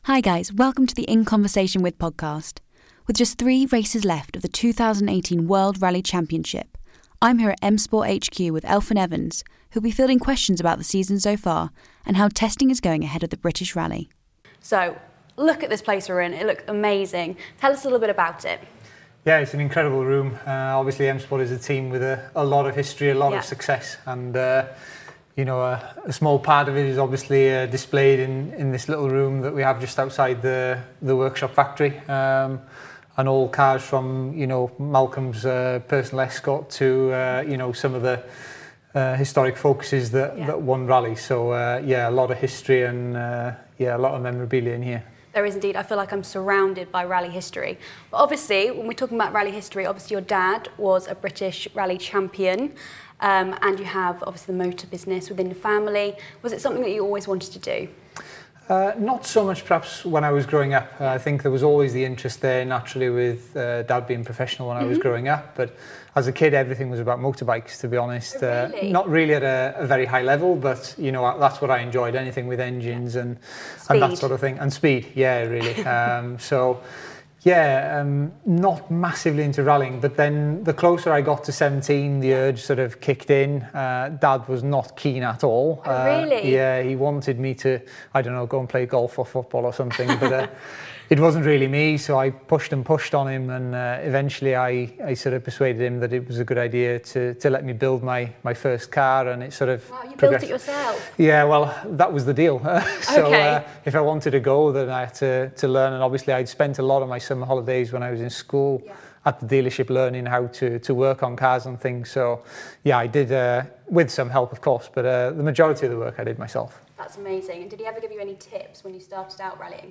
We’re joined In Conversation With British Rally driver Elfyn Evans.